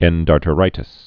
(ĕndär-tə-rītĭs)